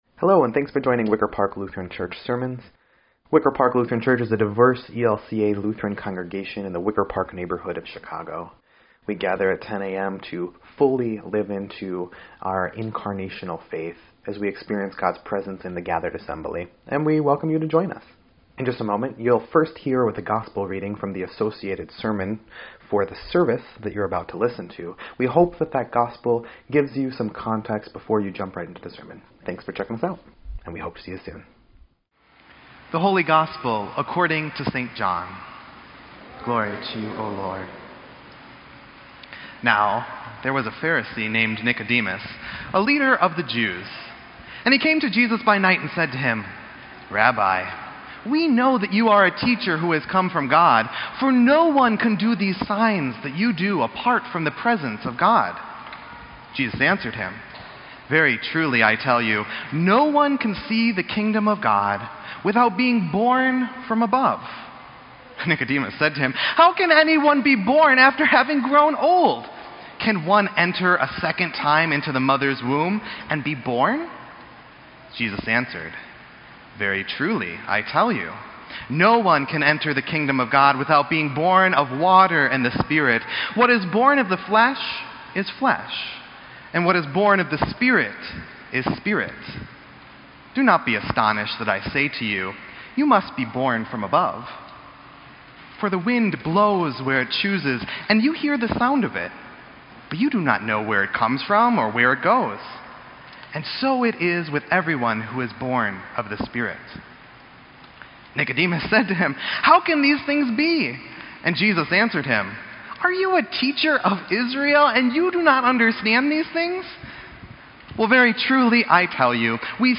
Sermon_5_27_18_EDIT.mp3